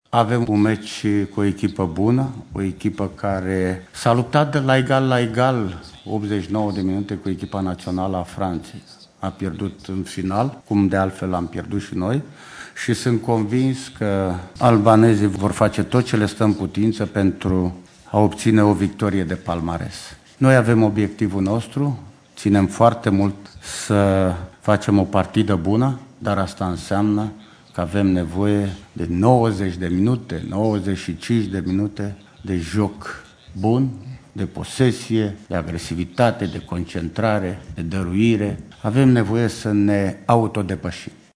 Anghel Iordănescu a vorbit şi despre adversarul de astăzi, de la Lyon: